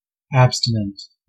Ääntäminen
Synonyymit celibate Ääntäminen US US : IPA : /ˈæb.stɪ.nn̩t/ IPA : /ˈæb.stə.nn̩t/ Haettu sana löytyi näillä lähdekielillä: englanti Käännös Adjektiivit 1. abstinent 2. enthaltsam Substantiivit 3.